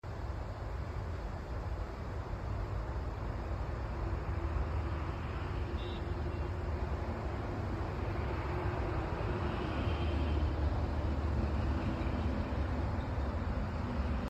Outdoor laser light/power: 25 watts sound effects free download